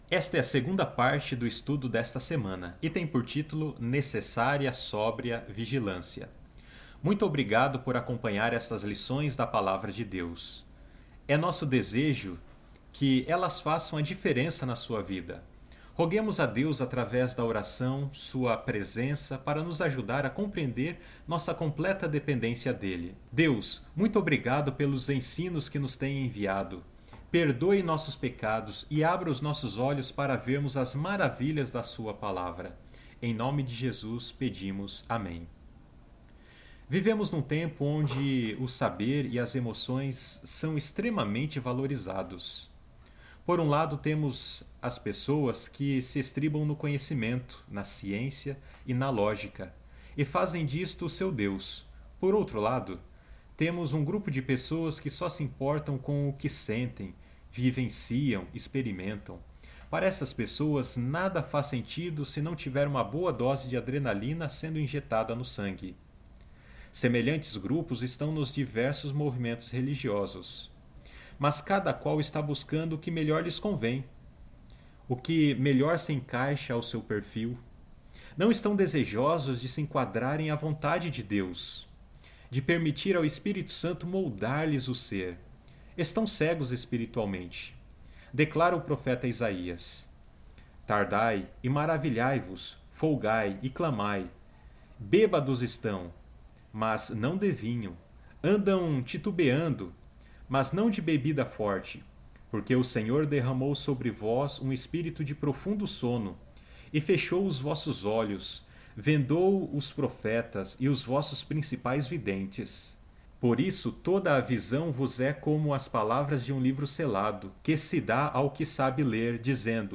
Áudios - Lição em Áudio